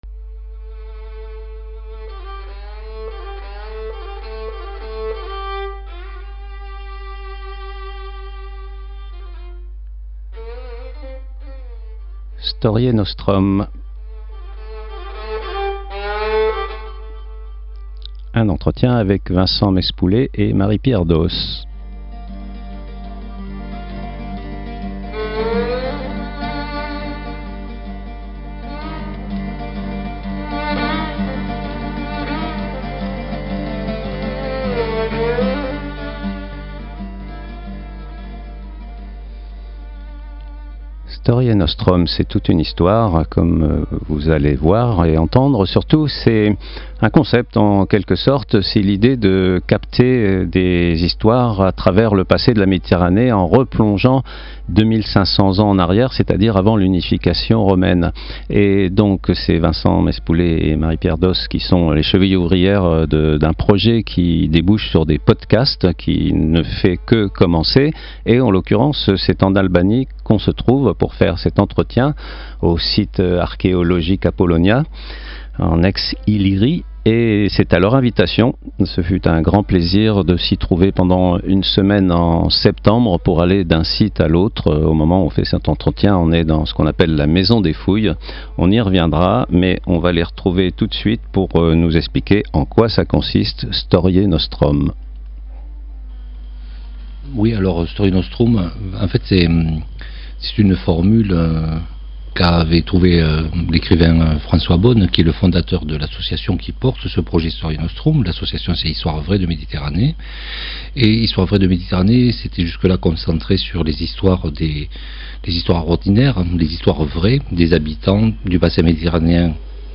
Présentation du projet à Radio Zinzine depuis l'Albanie